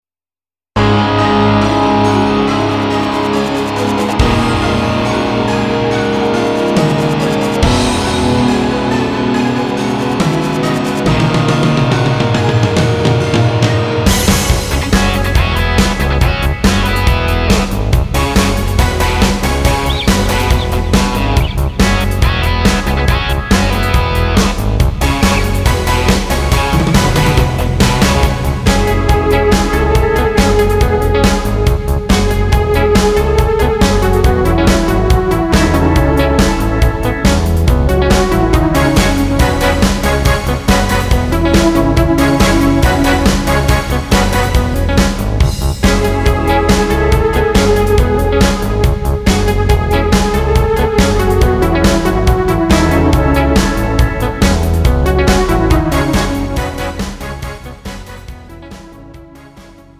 음정 (-6키)
장르 가요 구분 Pro MR